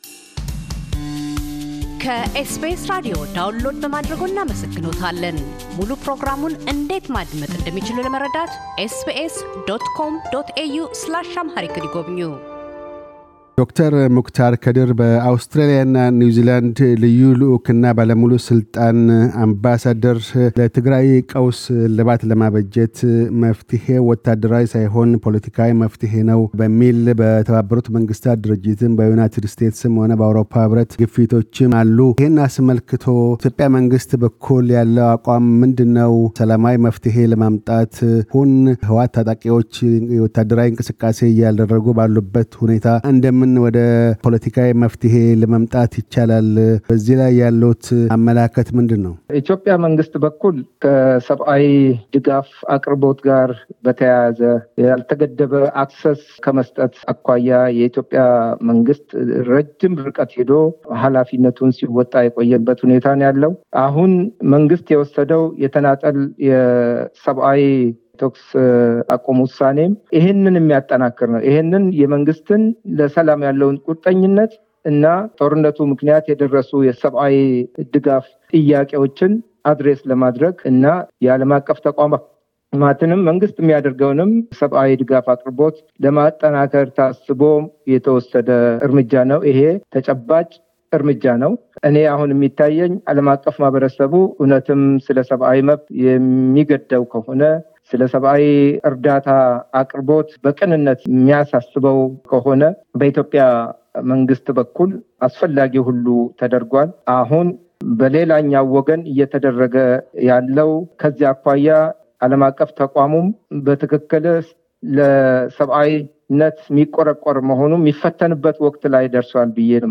ዶ/ር ሙክታር ከድር በአውስትራሊያና ኒውዚላንድ የኢፌዴሪ ልዩ ልዑክና ባለሙሉ ስልጣን አምባሳደር፤ በፖለቲካዊ መፍትሔ ፍለጋ፣ የዓለም አቀፉን ማኅበረሰብና የኢትዮጵያ ኤምባሲዎችና ቆንስላ ጽሕፈት ቤቶችን መቀነስ ጉዳይ አስመልክተው ይናገራሉ።